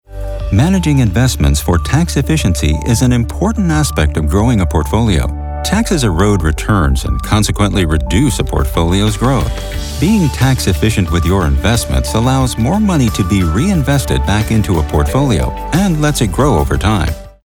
Mature Adult, Adult
Has Own Studio
standard us
06_TD_Ameritrade_spot.mp3